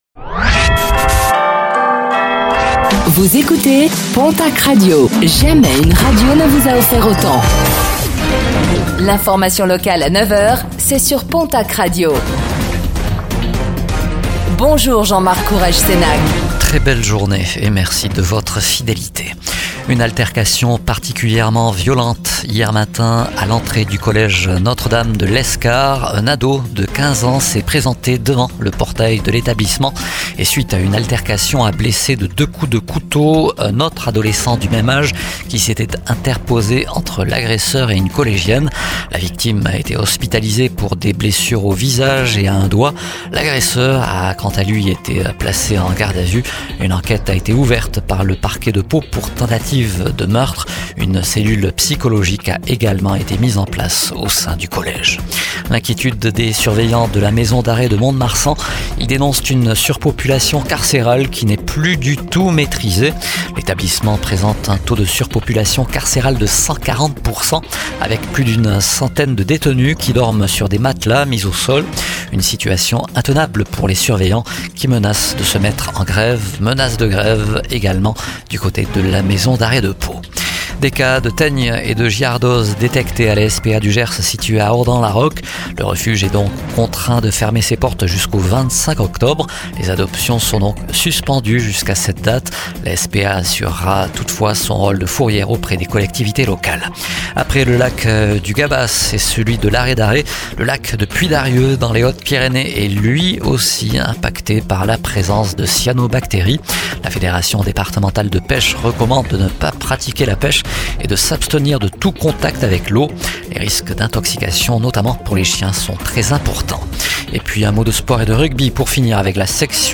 Réécoutez le flash d'information locale de ce jeudi 16 octobre 2025